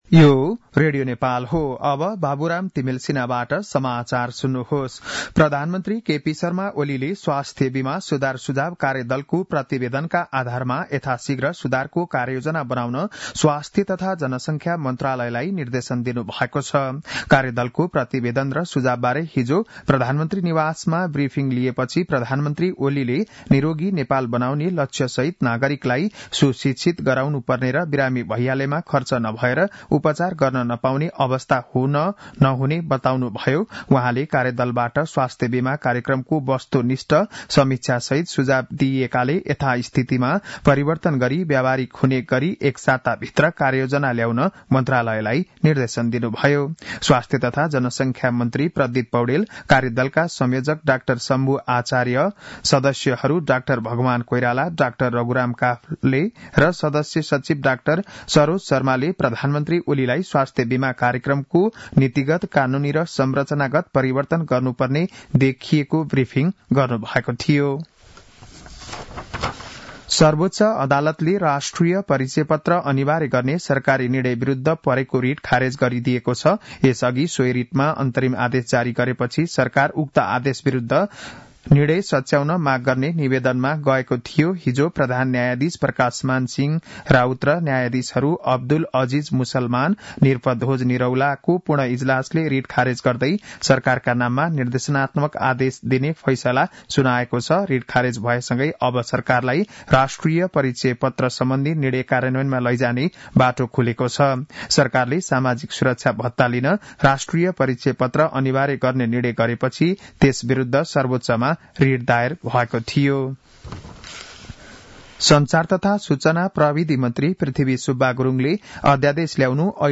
बिहान ११ बजेको नेपाली समाचार : ८ माघ , २०८१
11-am-news-1-9.mp3